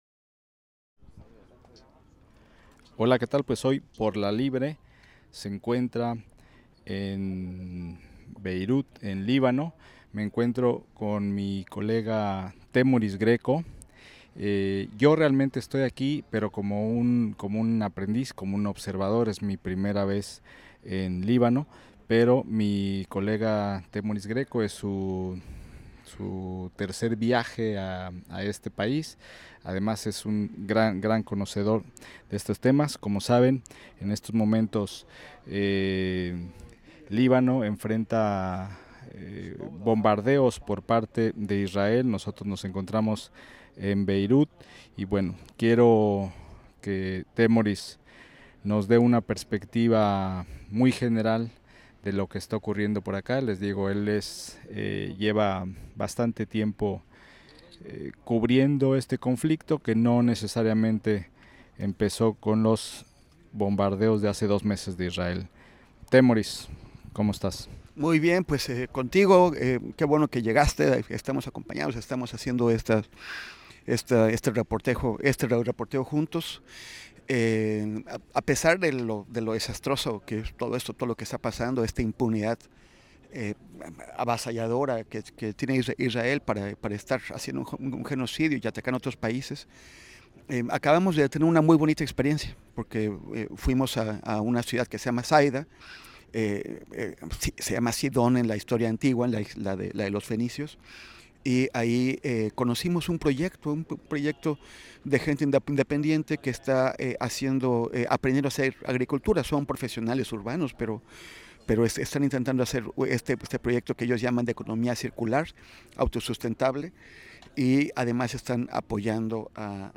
durante el programa de radio Por la Libre que contó con una transmisión especial desde Líbano.